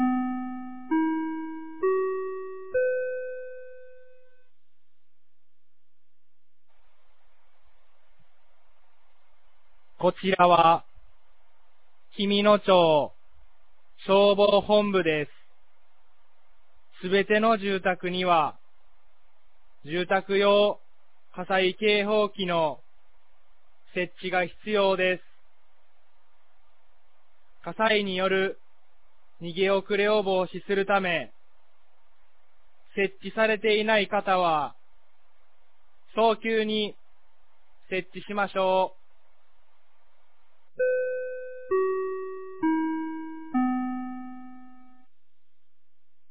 2025年04月26日 16時00分に、紀美野町より全地区へ放送がありました。